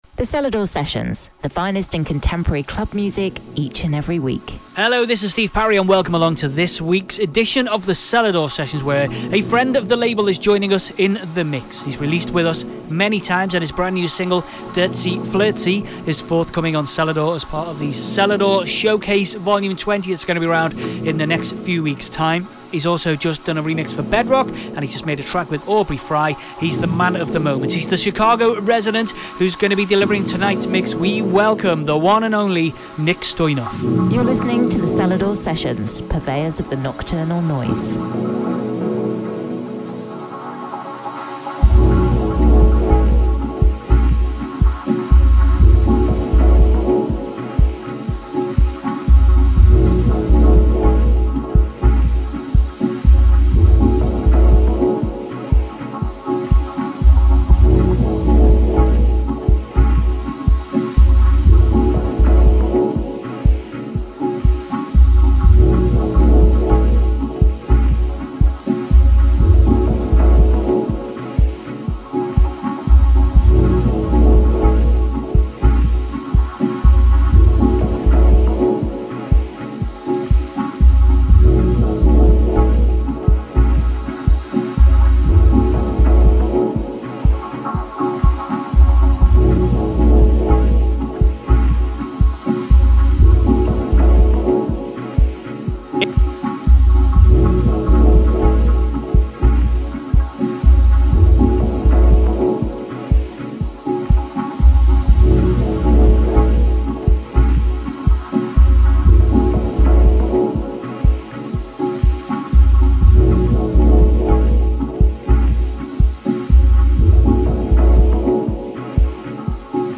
music DJ Mix in MP3 format
Genre: Progressive house